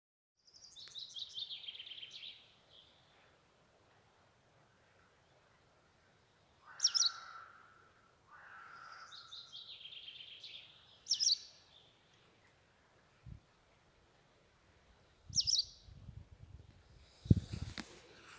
White Wagtail, Motacilla alba
StatusSpecies observed in breeding season in possible nesting habitat